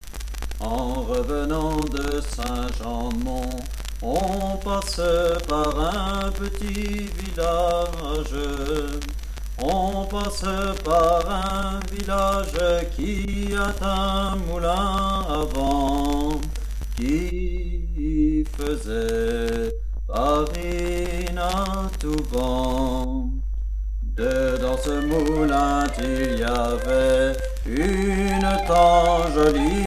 Musique traditionnelle de Vendée
Pièce musicale éditée